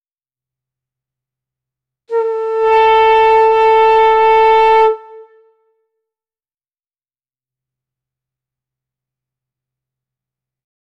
A quick, sharp air horn sound followed by silence.
a-quick-sharp-air-horn-sound-followed-by-silence-mkd2mnvm.wav